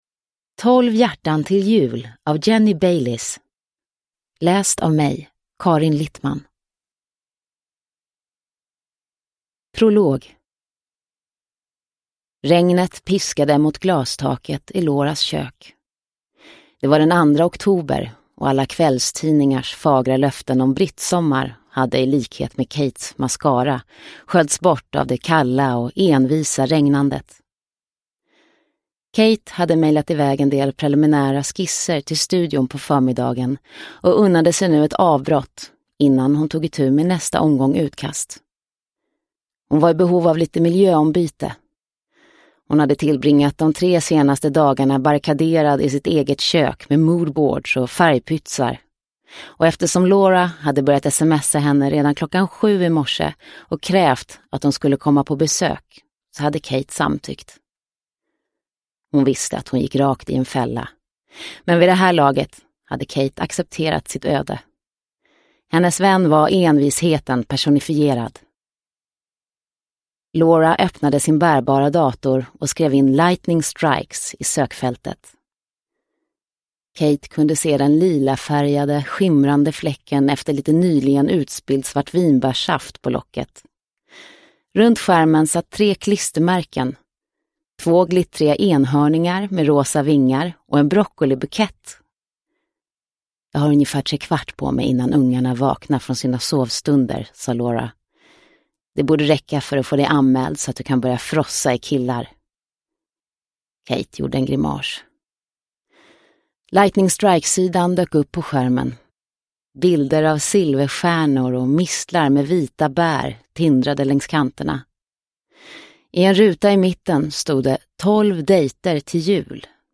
Tolv hjärtan till jul – Ljudbok – Laddas ner